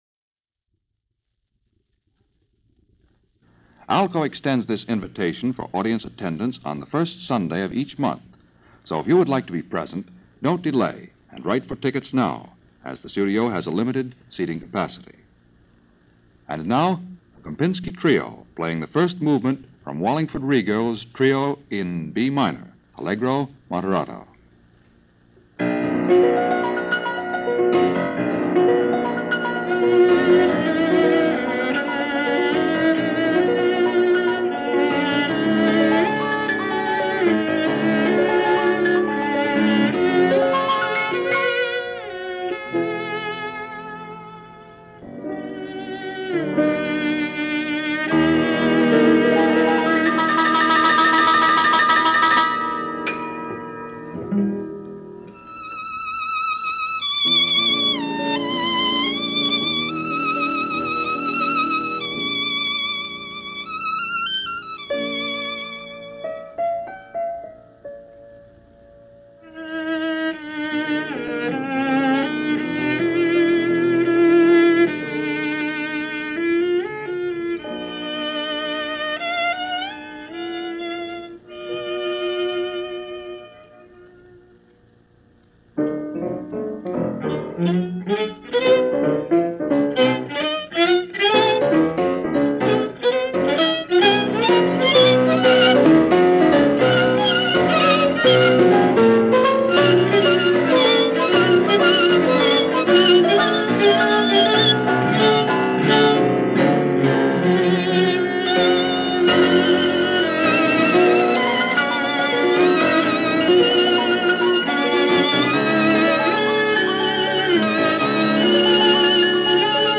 radio broadcast performance